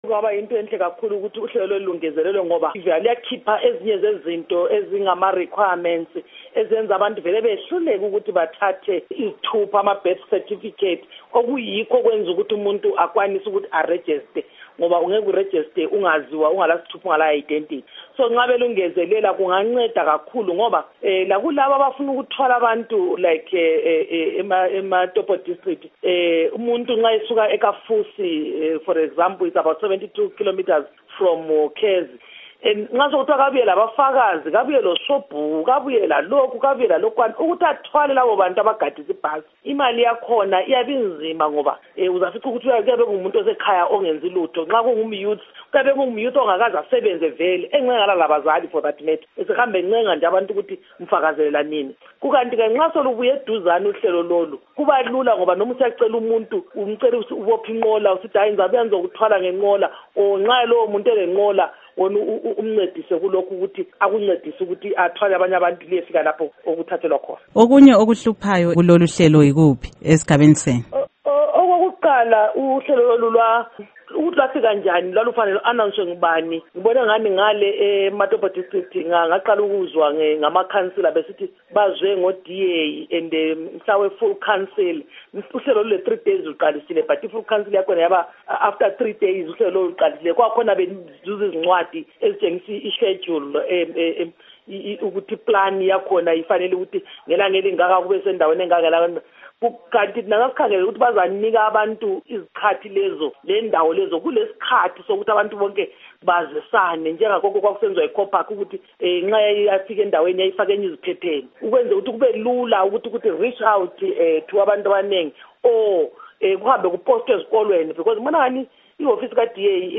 Ingxoxo LoSenator Sithembile Mlotshwa